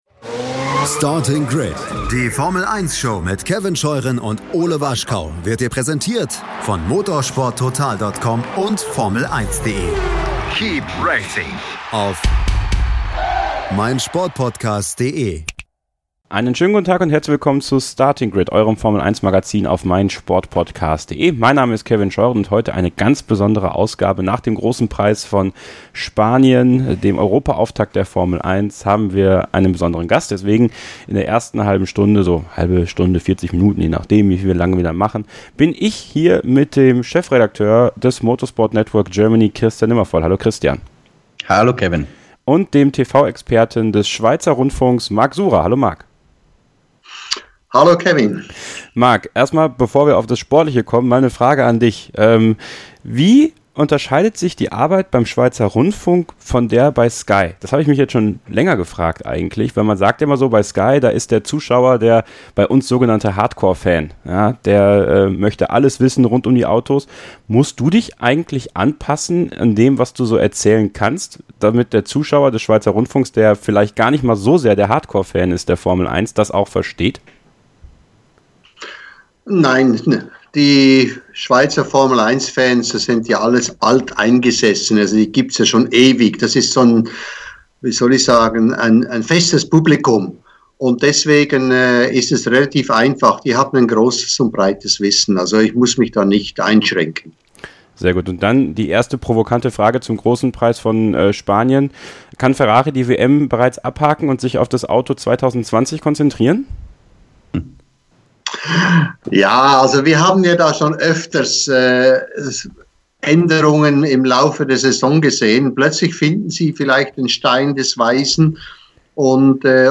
Marc Surer zu Gast Es gibt jede Menge zu besprechen und das machen wir heute in zwei geteilten Teams.
Der Experte vom SRF war in Montmelo vor Ort und berichtet aus erster Hand. Was muss Ferrari tun, um die Mercedes-Übermacht zu beenden?